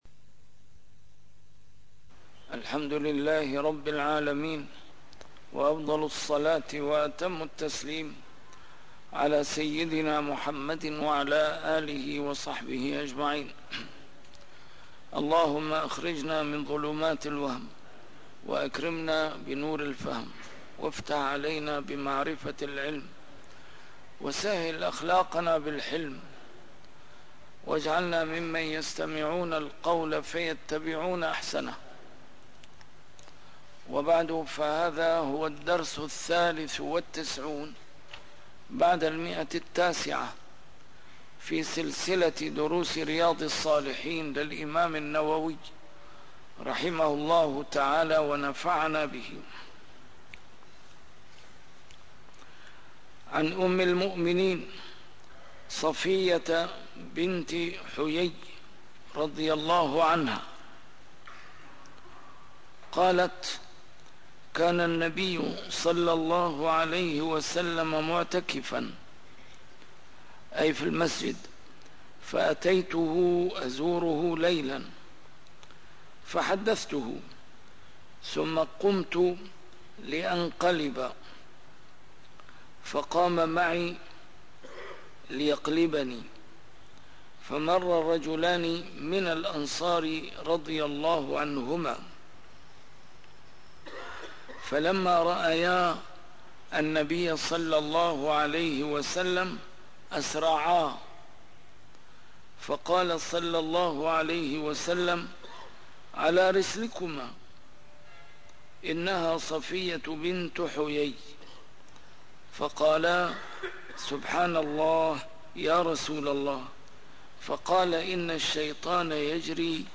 A MARTYR SCHOLAR: IMAM MUHAMMAD SAEED RAMADAN AL-BOUTI - الدروس العلمية - شرح كتاب رياض الصالحين - 993- شرح رياض الصالحين; بابُ المنثورات والمُلَح